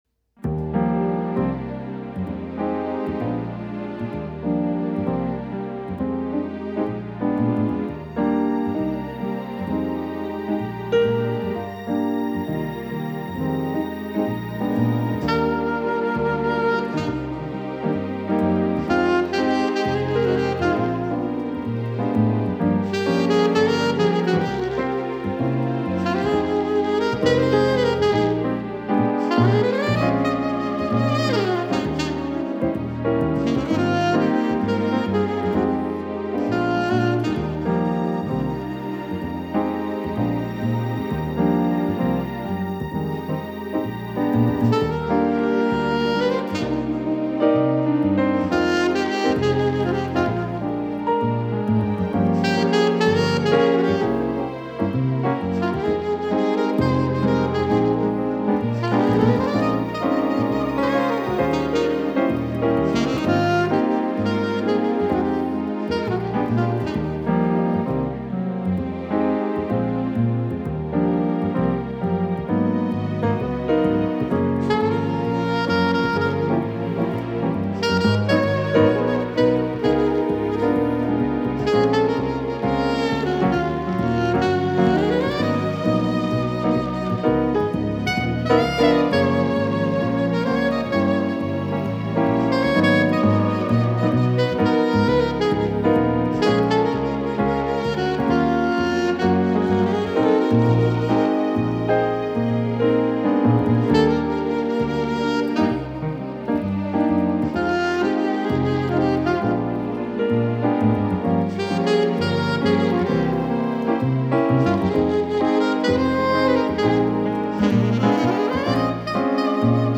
Piano and Sax Duo